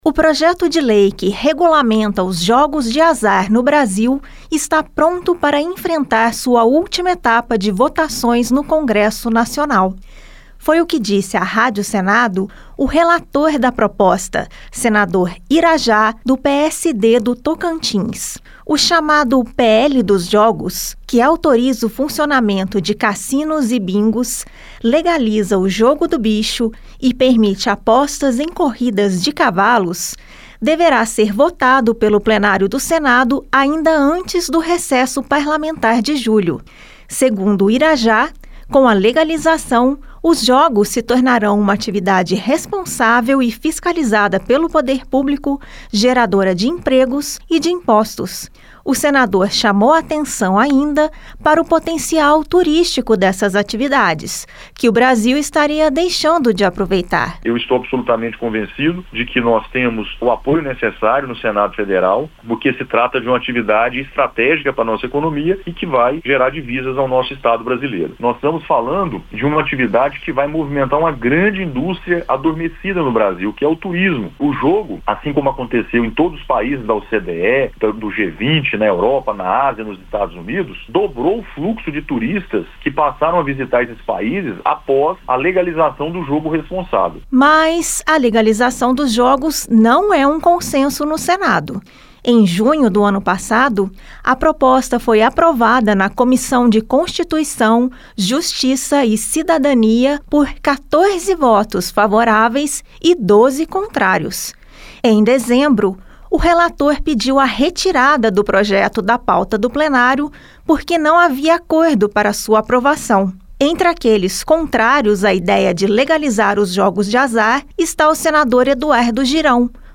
O projeto de lei que regulariza os bingos e cassinos do país poderá ser votado no Plenário antes do recesso parlamentar, que começa no dia 18 de julho. O relator, senador Irajá (PSD-TO), disse à Rádio Senado que está confiante no acordo de lideranças para a proposta ser analisada nas próximas três semanas. Mas o projeto enfrenta resistências, a exemplo do senador Eduardo Girão (Novo-CE), que alertou para a ludopatia e endividamento dos apostadores, como já ocorre com as bets.